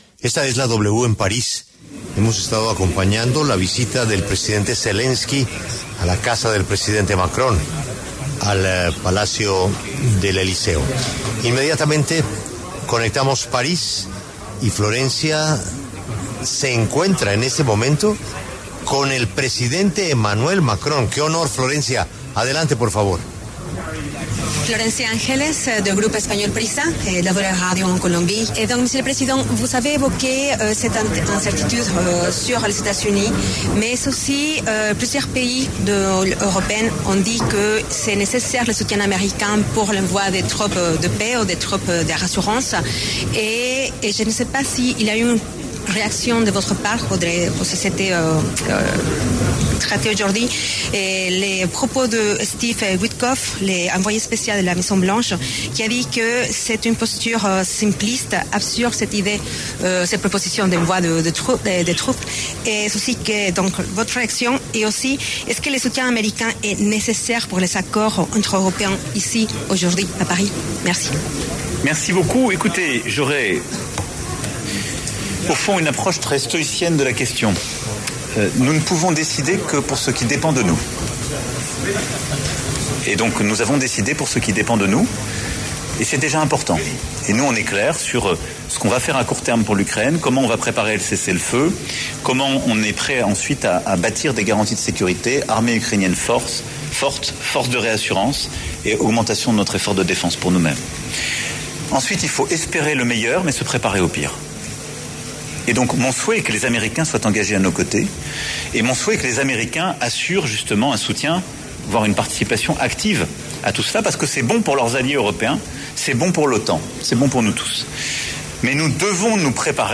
El presidente de Francia, Emmanuel Macron, pasó por los micrófonos de La W para hablar sobre su reunión con Zelenski y la participación de EE.UU. en los acuerdos de paz con Rusia.
La W conversó con el presidente de Francia, Emmanuel Macron, sobre la visita de Zelenski al país, donde se han mantenido diálogos de alto nivel sobre el rearme de la Unión Europea y los apoyos a Ucrania en medio de su guerra con Rusia, así como de la participación de Estados Unidos en el plan de seguridad de la región.